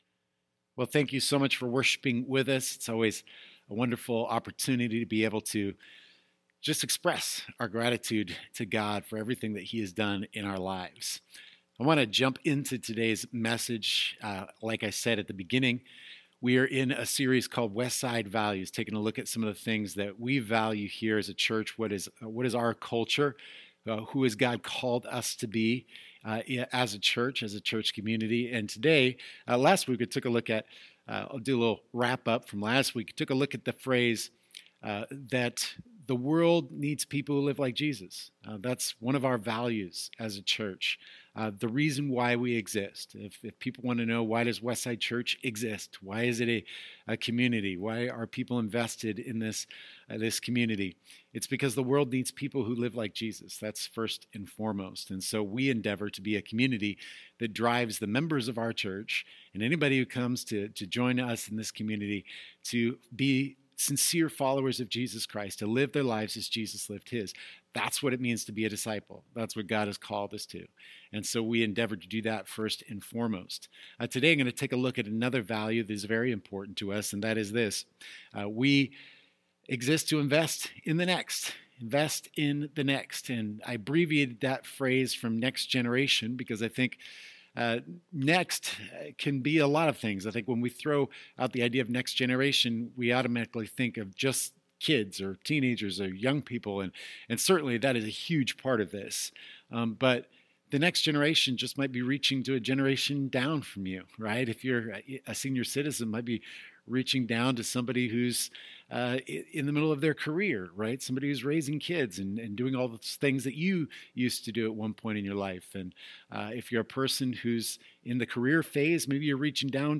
A few years ago, we set out to put words to those passions and we compiled a list of our core values. During this sermon series, we will be sharing them with you!